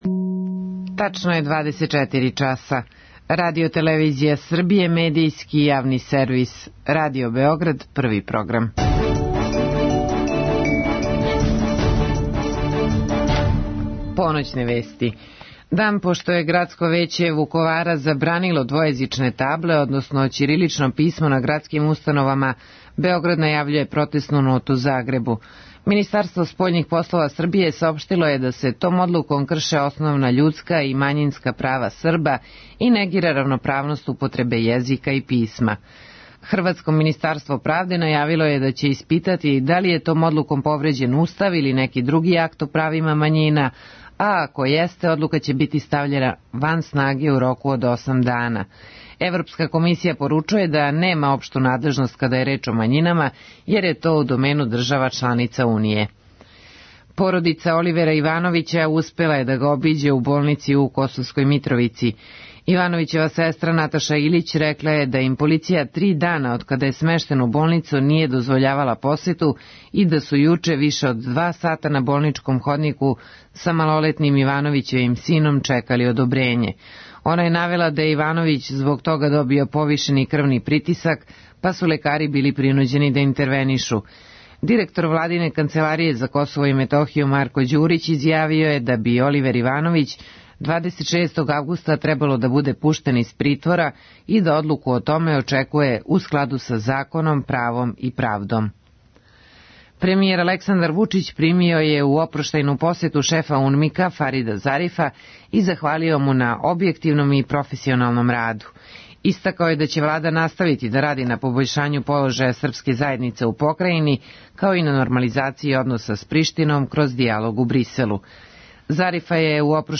Разговор и добра музика требало би да кроз ову емисију и сами постану грађа за снове.
Гост је драмски уметник Милан Цаци Михаиловић. Он ће говорити о лепоти и изазовима глуме, и о многобројним улогама оствареним у позоришту, на филму, на радију и телевизији.
У другом делу емисије - од два до четири часа ујутро - као неку врсту мале личне антологије слушаћемо песме у избору и интерпретацији Милана Цација Михаиловића.